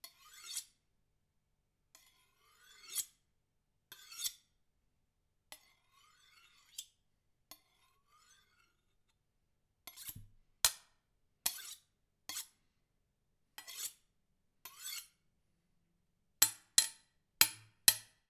Kitchen Knife Scratches Sound
household